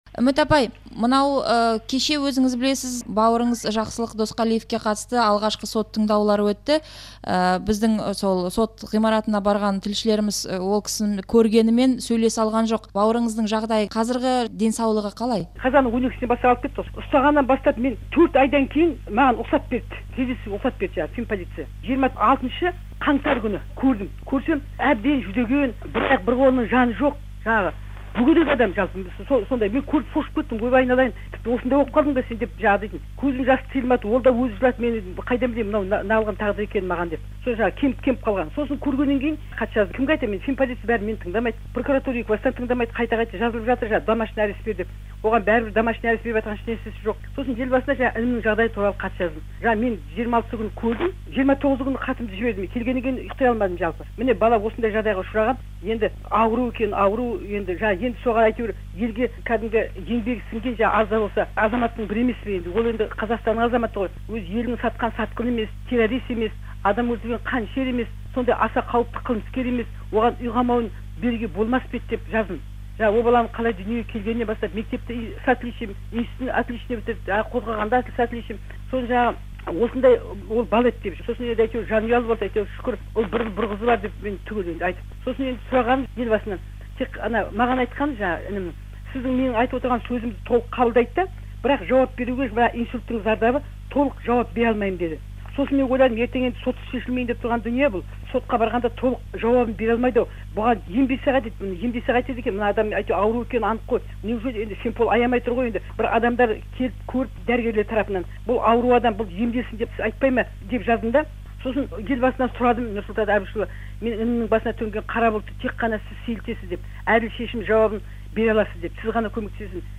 сұқбаты